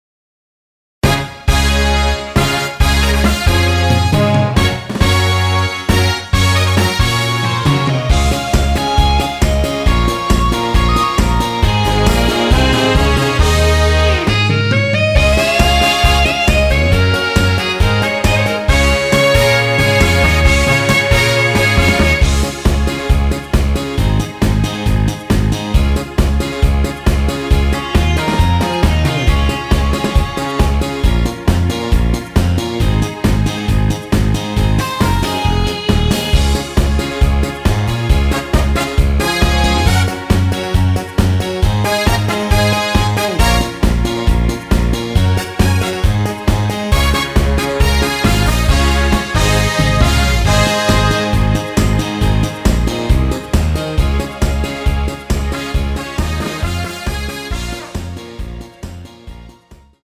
원키에서(+3)올린 MR입니다.
Db
앞부분30초, 뒷부분30초씩 편집해서 올려 드리고 있습니다.
중간에 음이 끈어지고 다시 나오는 이유는